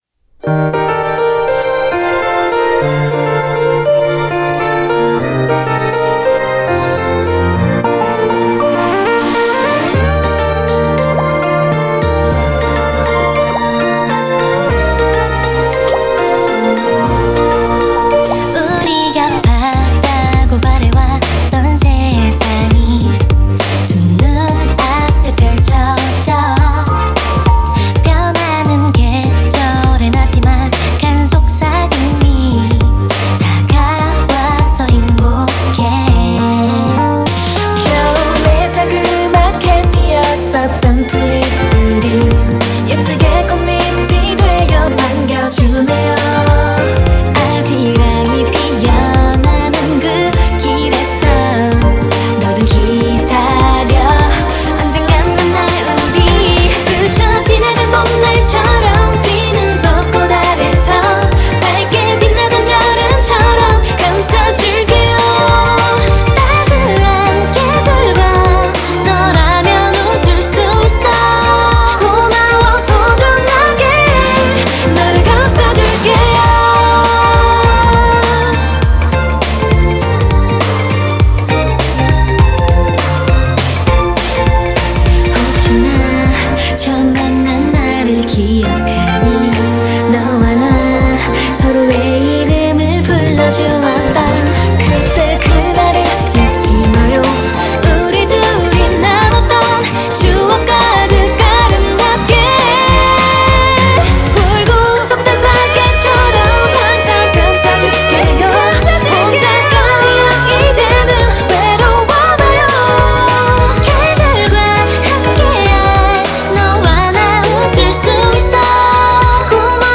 BPM51-101
Audio QualityPerfect (High Quality)